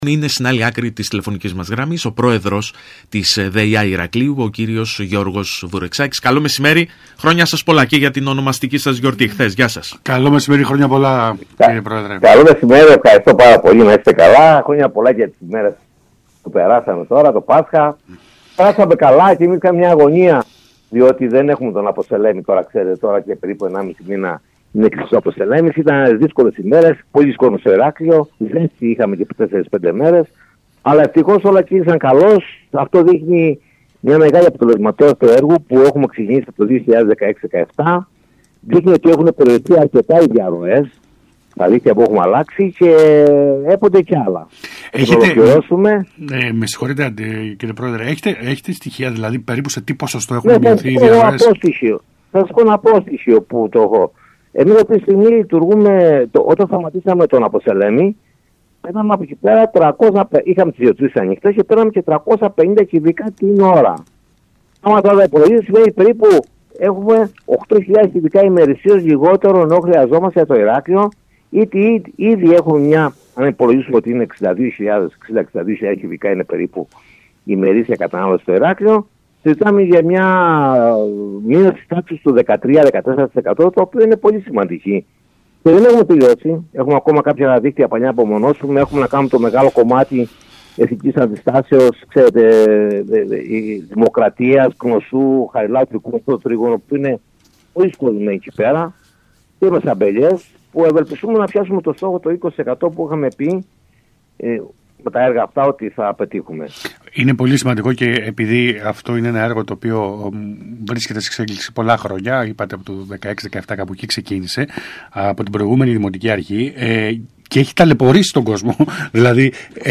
Μιλώντας στον ΣΚΑΪ Κρήτης 92.1